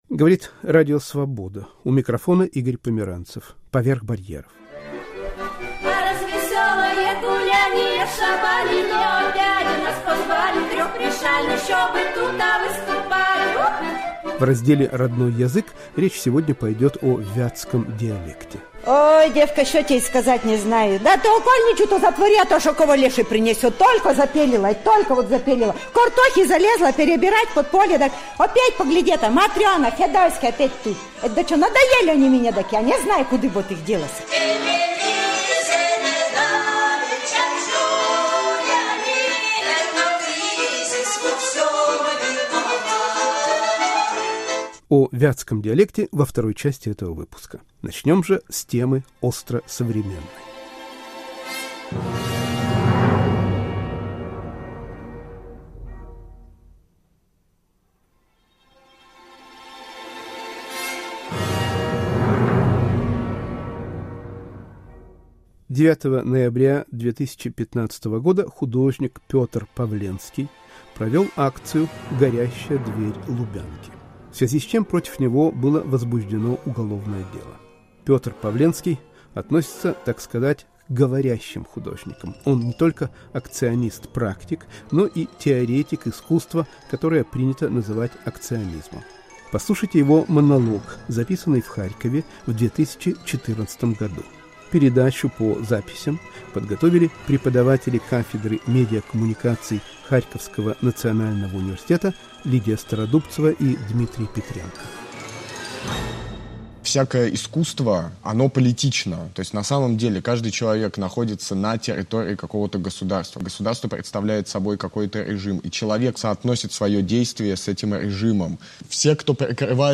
Передача с участием вильнюсских музыкантов и альпинистов ** Психолог и поэт Борис Херсонский (Одесса) вспоминает о работе в психиатрической больнице * «Родной язык». Вятский диалект